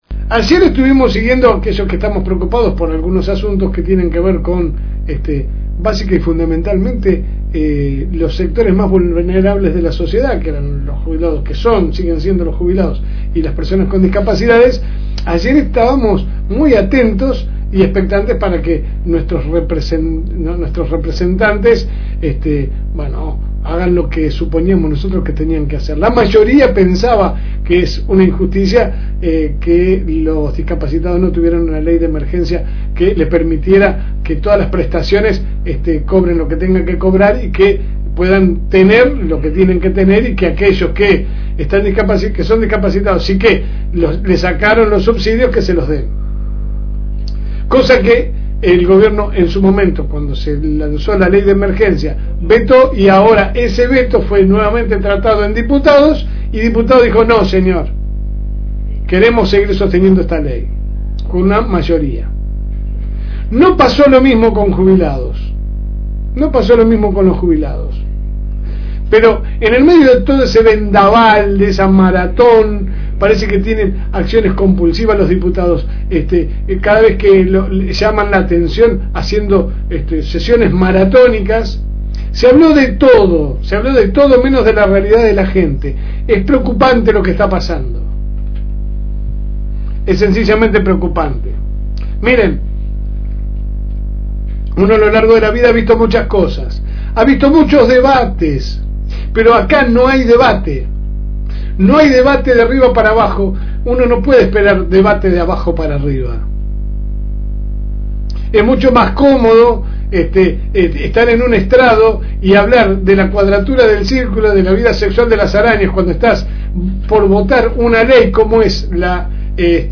Comentario
Su programa sale de lunes a viernes de 10 a 12 HS por el aire de la FM Reencuentro 102.9